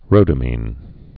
(rōdə-mēn)